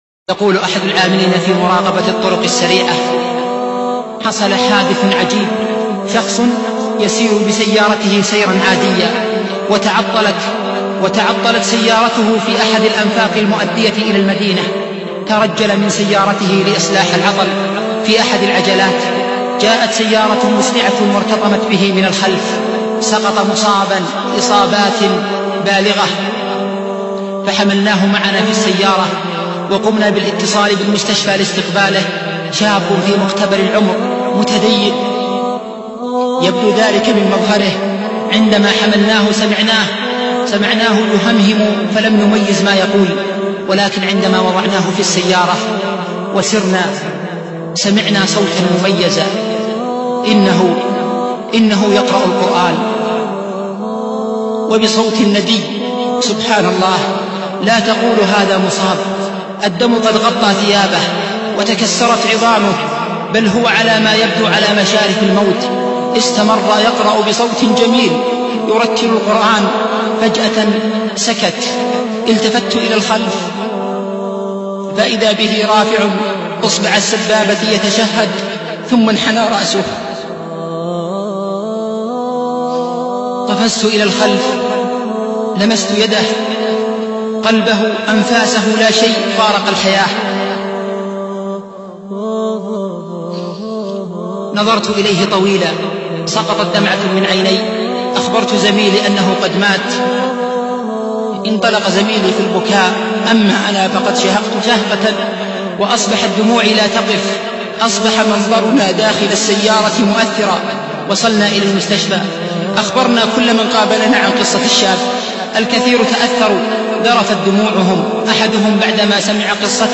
مقطع مميز: همسة لكل غالي مقطع يصف فيها الشيخ قصة حُسن الخاتمة لشاب